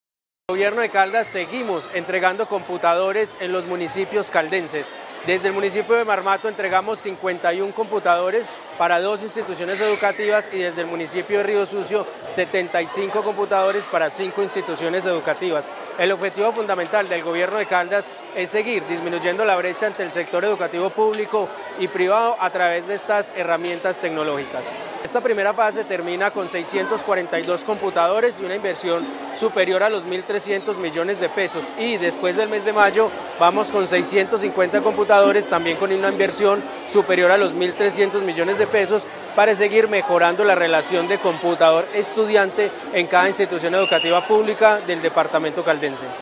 Gobernador encargado, Ronald Fabián Bonilla.